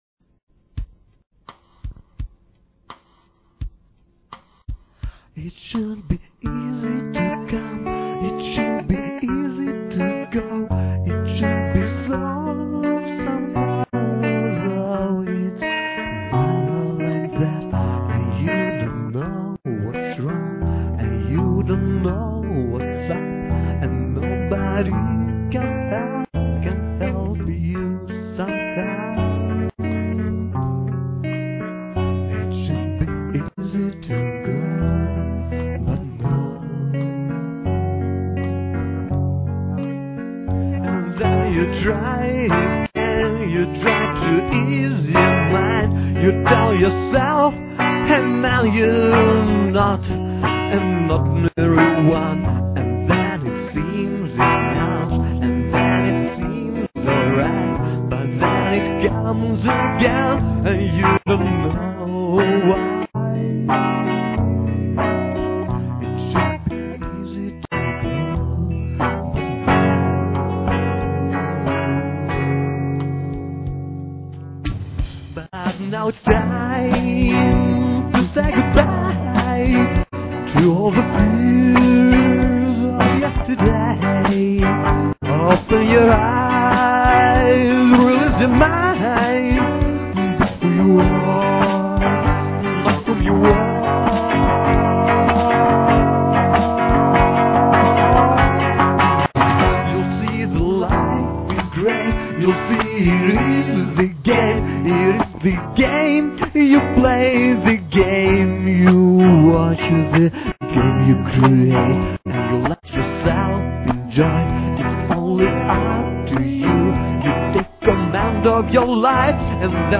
MM 84-97
D F#m Hm H7 (Cdim) H7